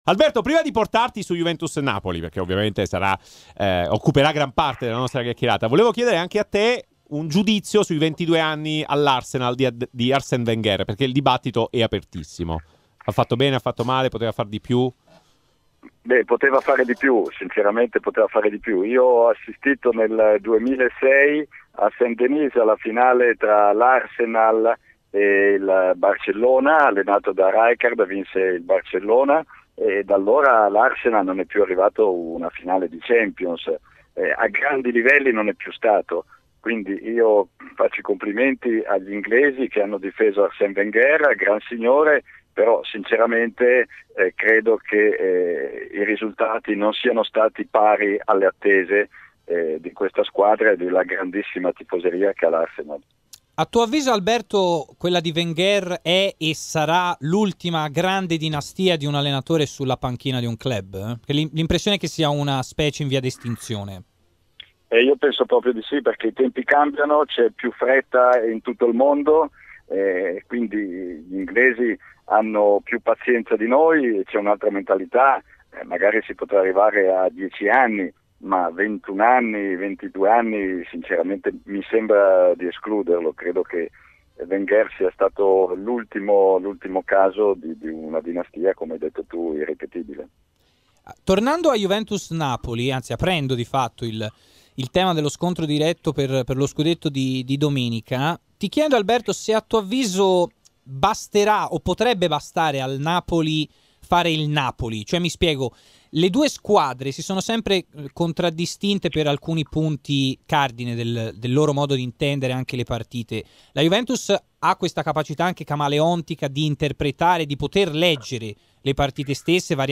in apertura del suo intervento all'interno del Live Show ha commentato così l'addio di Wenger all'Arsenal
In studio